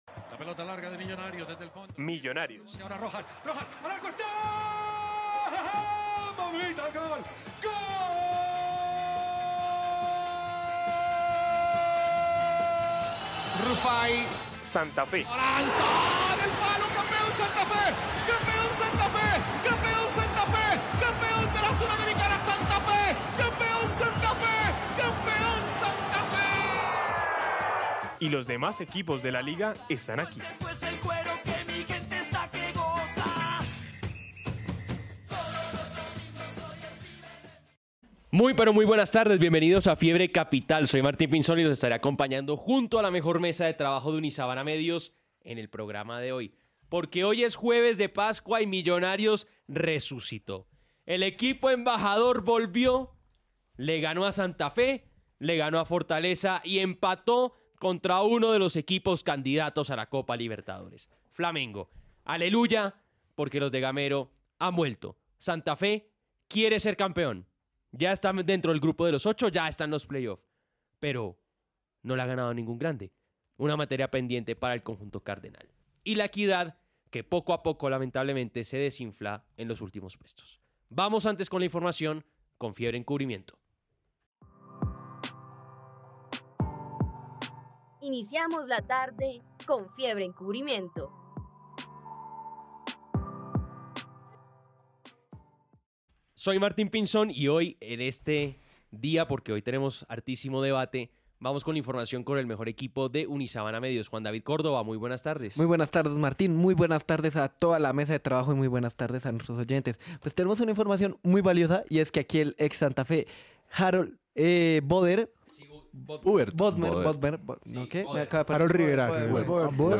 Programa radial de análisis del fútbol colombiano y latinoamericano, transmitido por Unisabana Medios el 4 de abril de 2024. Se discuten los resultados recientes de los equipos colombianos en la Liga BetPlay y la Copa Libertadores, así como el rendimiento del fútbol femenino.